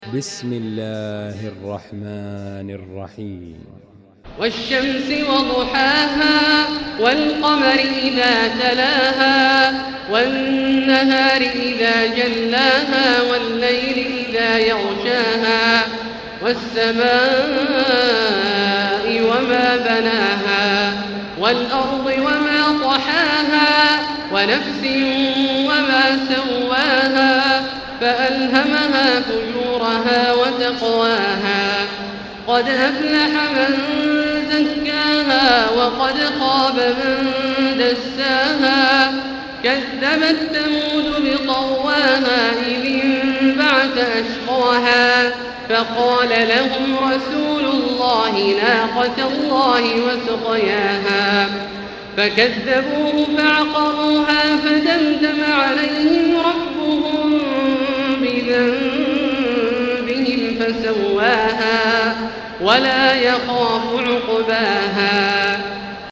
المكان: المسجد الحرام الشيخ: فضيلة الشيخ عبدالله الجهني فضيلة الشيخ عبدالله الجهني الشمس